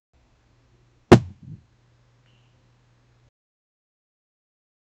Um estalo energético, como um pop ou explosão controlada, que indica a estabilização do poder.
um-estalo-energtico-como--fynx6iiw.wav